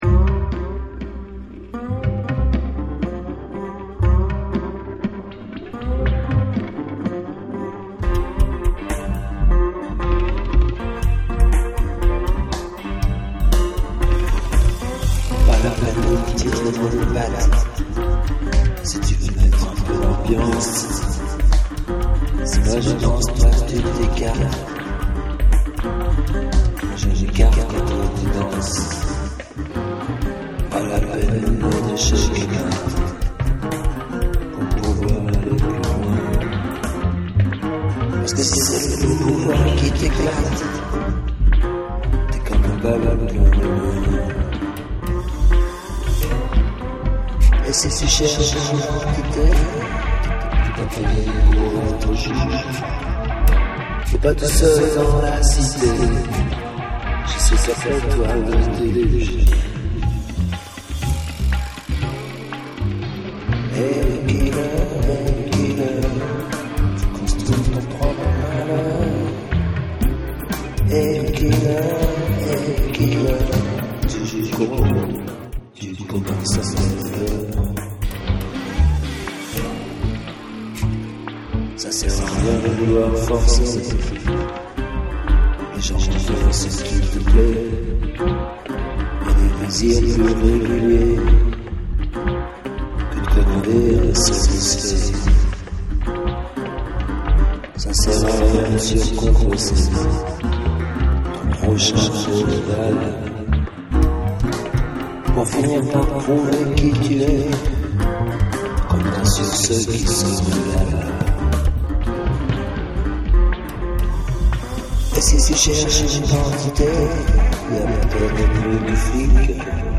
maquette version #10
Ego-Killer_slide10drBass___low.mp3